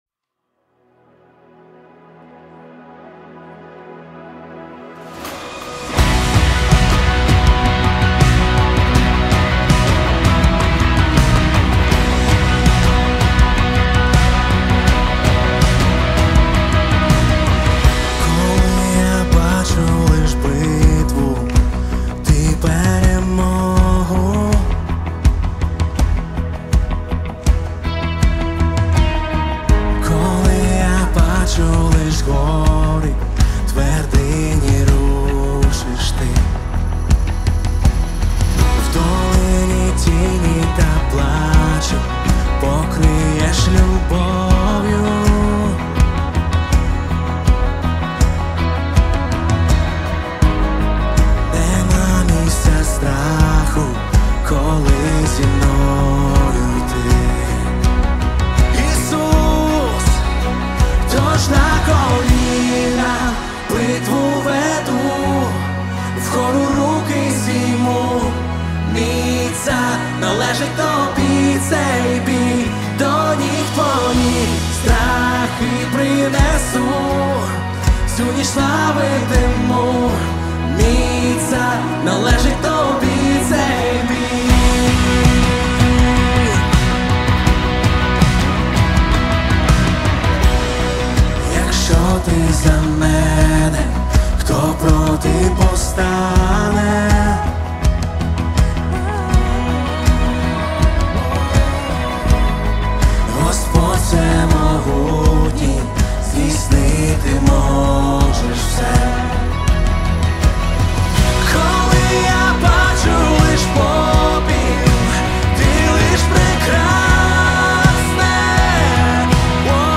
383 просмотра 60 прослушиваний 22 скачивания BPM: 162